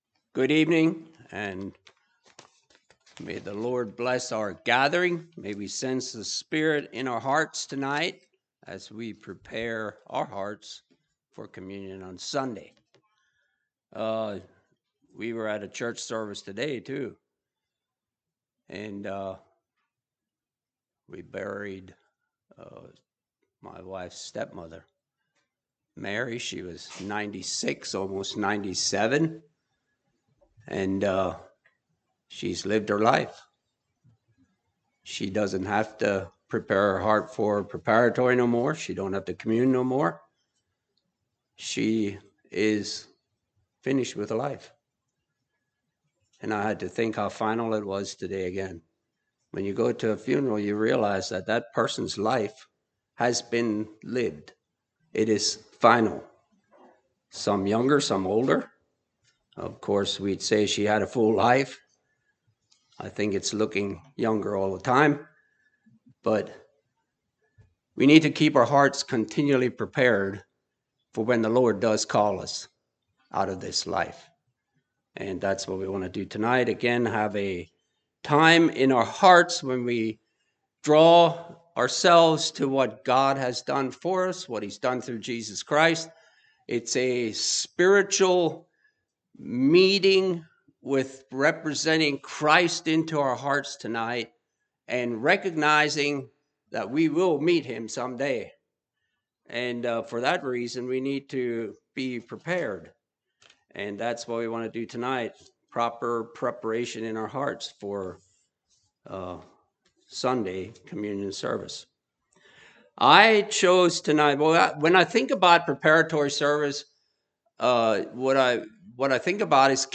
Preparatory Service - Word of Life Mennonite Fellowship
Listen to sermon recordings from Word of Life Mennonite Fellowship.